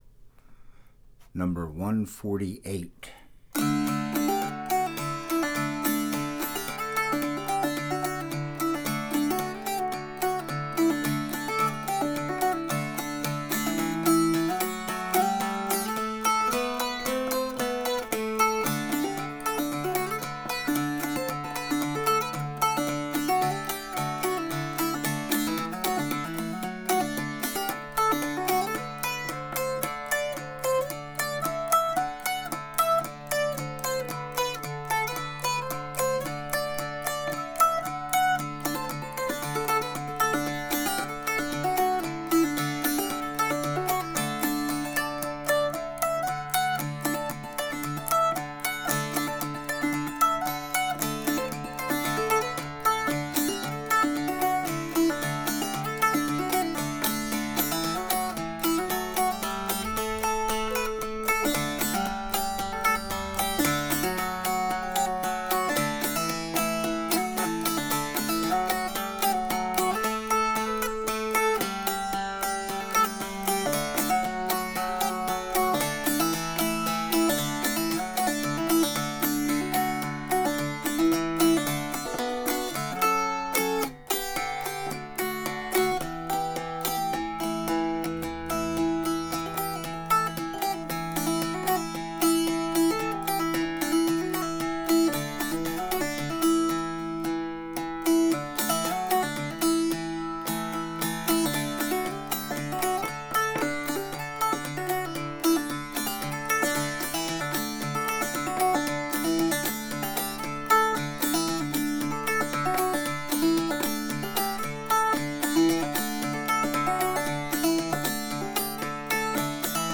# 148 Electric Hourglass Dulcimer - Heinrich Dulcimers
All reclaimed Chestnut. the wood is over a hundred years old.
Click on the play button below to hear this dulcimer.